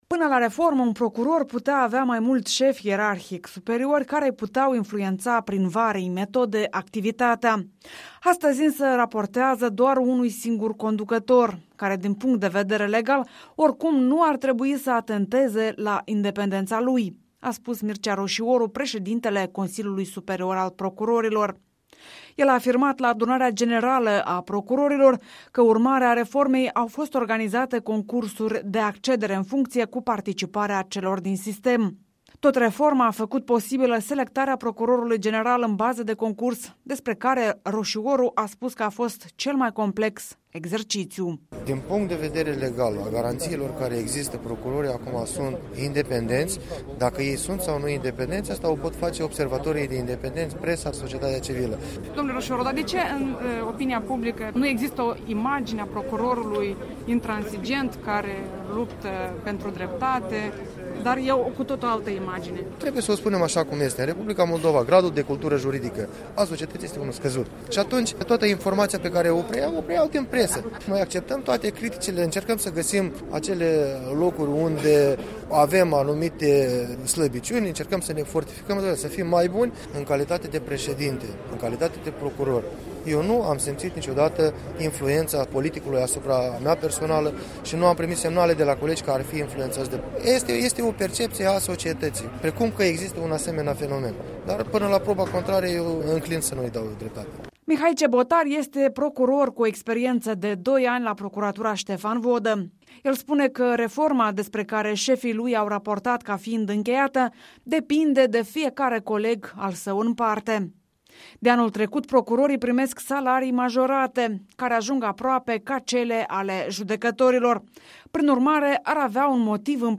Adunarea generală a procurorilor mdoldoveni.